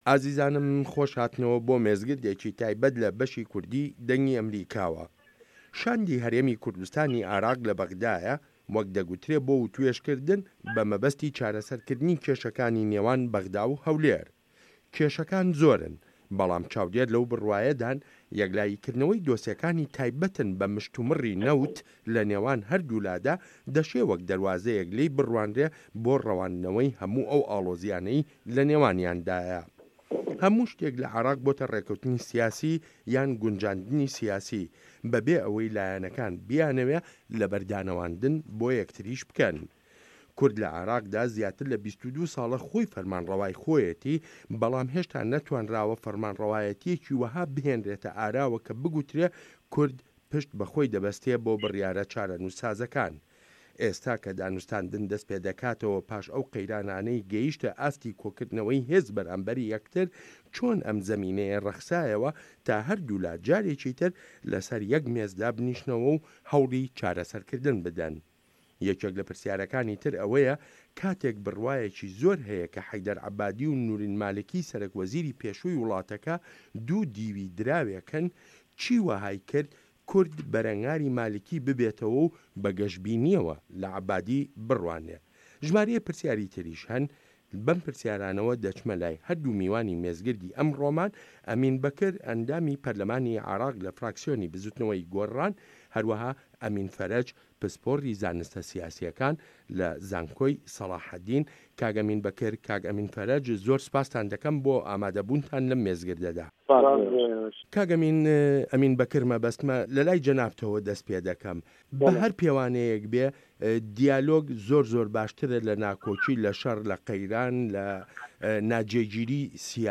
مێزگرد: هه‌ولێر و به‌غدا به‌ره‌و کوێ